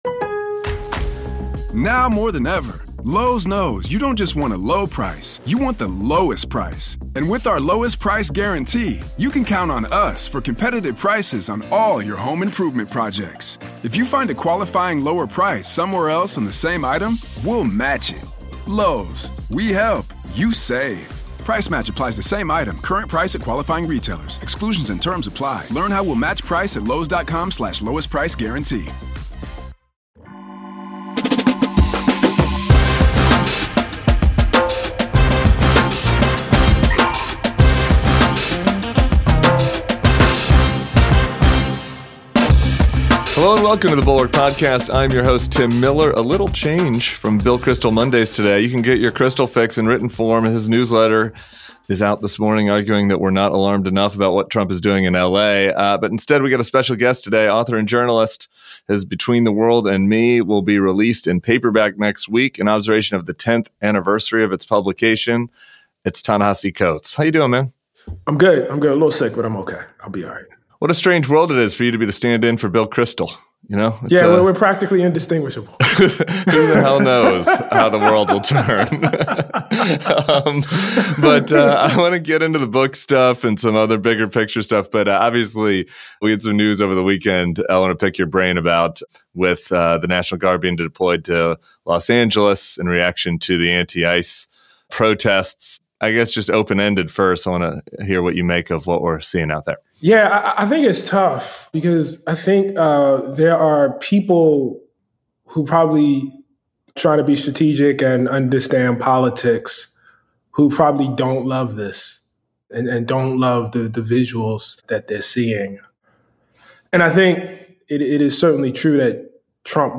And in a special bonus segment from our live show last Friday